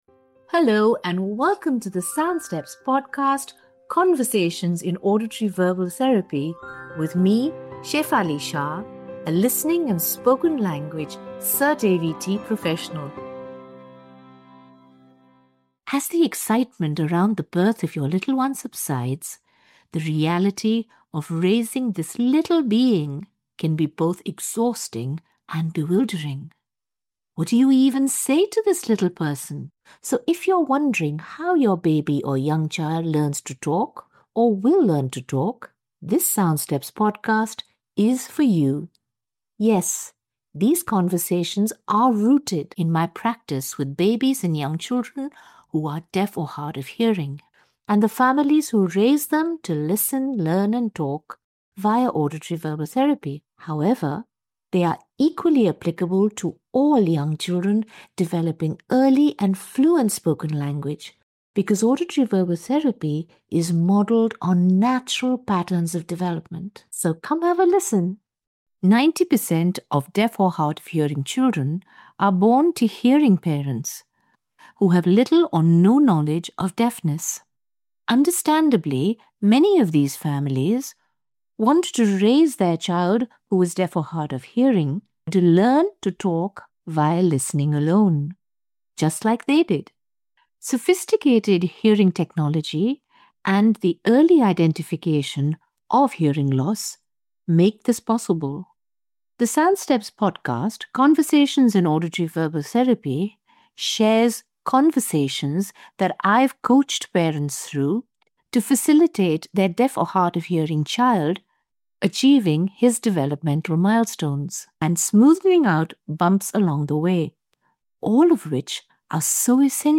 Their conversation shows how frequency and variety are essential in building a child’s comprehension and ability to talk fluently.